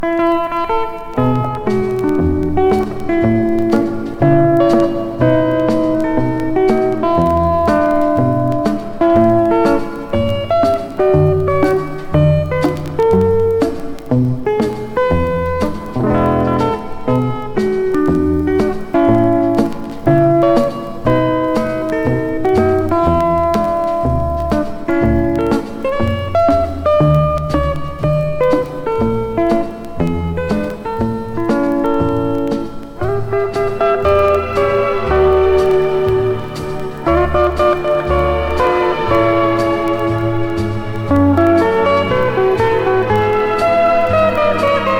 タイトル通りのハリウッド録音。
きめ細かく行き届いたギタープレイと、素晴らしいストリングスアレンジで非日常の夢見を与えてくれます。
Pop, Jazz, Country　USA　12inchレコード　33rpm　Stereo